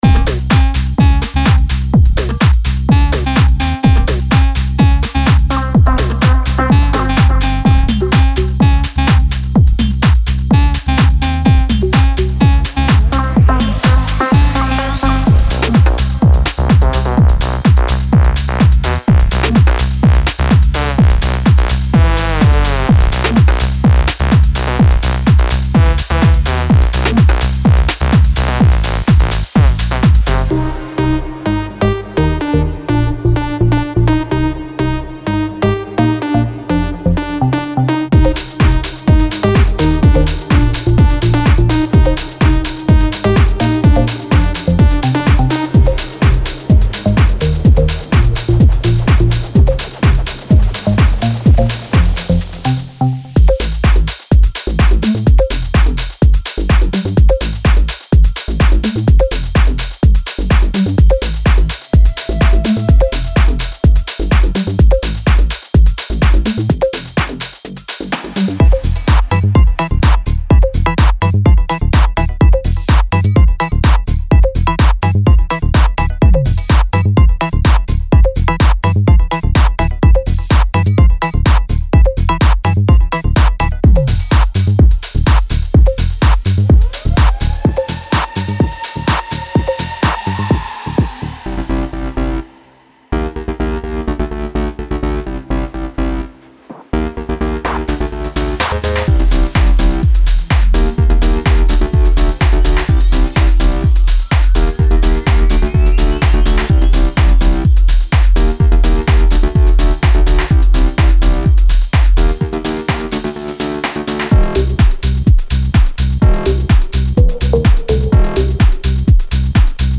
Genre:Tech House
40 Synth Loops
26 Bass Loops
25 Full Drum Loops
25 Percussion Loops